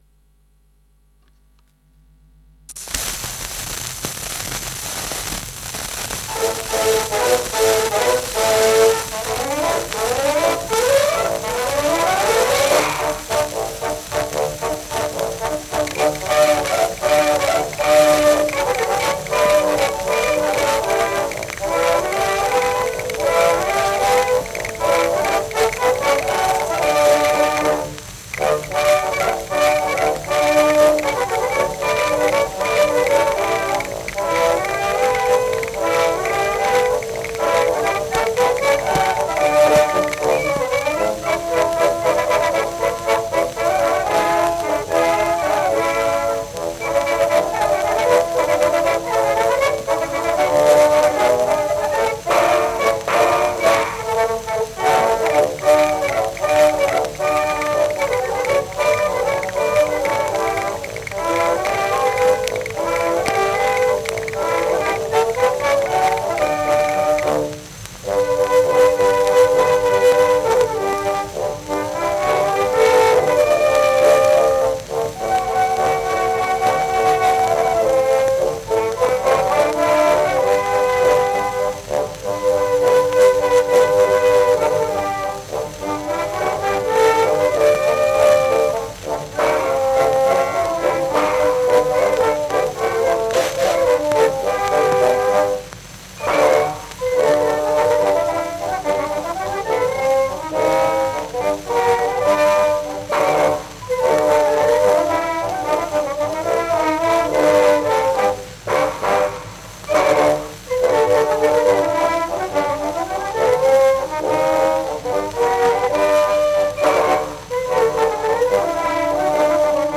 Исполнитель: Dacapo-Orchester
Дата и место записи: около 1906 г., Берлин.
Перевод названия: Студенческий вальс